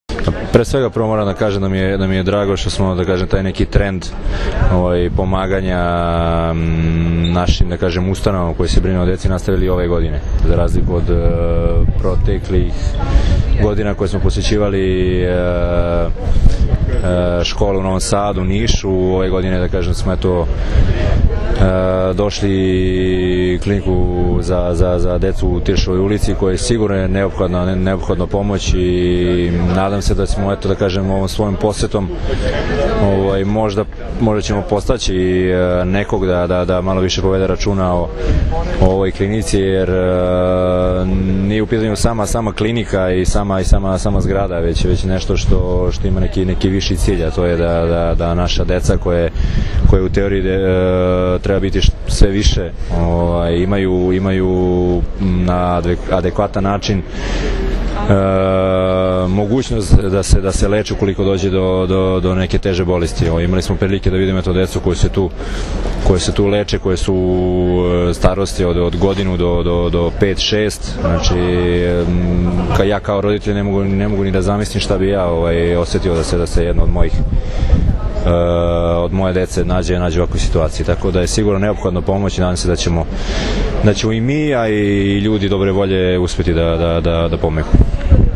IZJAVA DRAGANA STANKOVIĆA